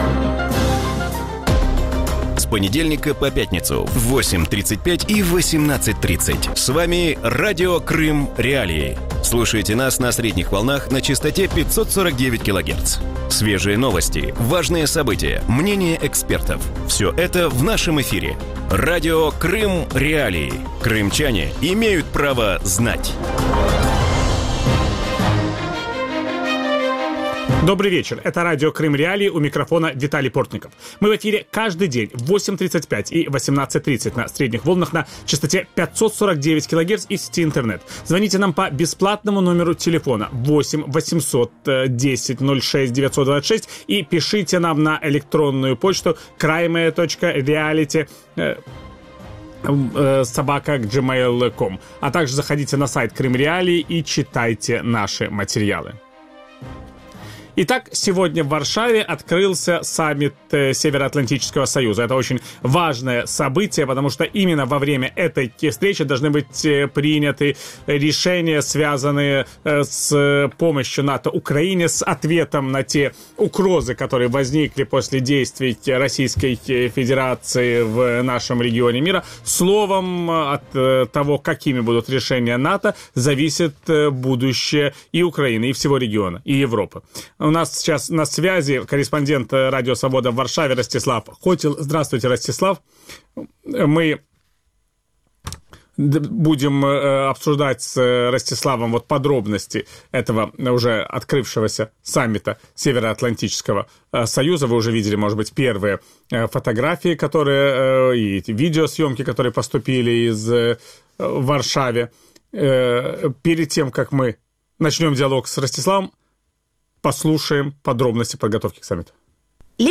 В вечернем эфире Радио Крым.Реалии обсуждают перспективы саммита стран-участниц НАТО в Варшаве. Удастся ли странам договориться об усилении военного контингента в Европе, какие примут решения по сдерживанию России и усилится ли сотрудничество НАТО и Украины?
Ведущий: Виталий Портников.